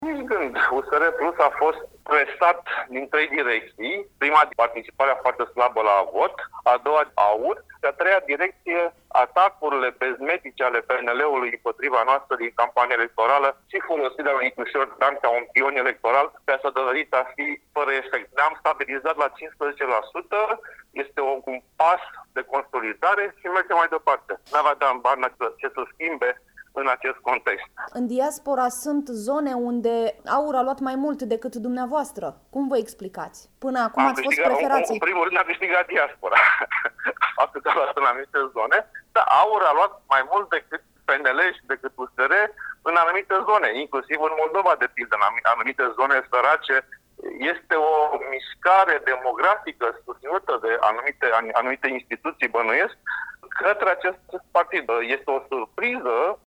Într-un inteviu acordat Europa FM, Ghinea spune că Barna nu putea obține rezultate mai bune: